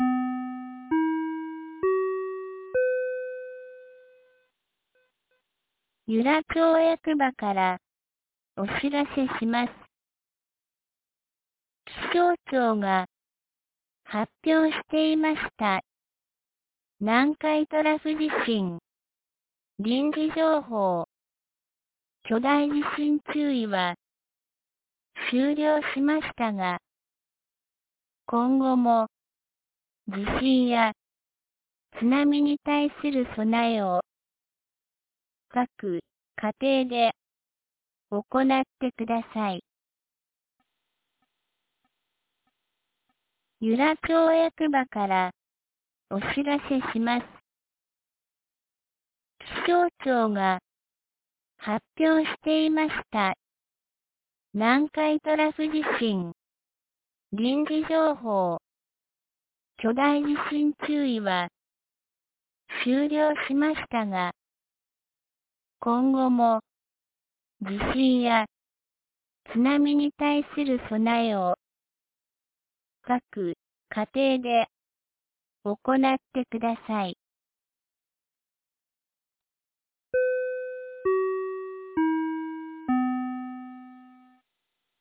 2024年08月16日 12時22分に、由良町から全地区へ放送がありました。